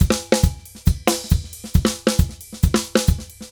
Drums_Merengue 136-3.wav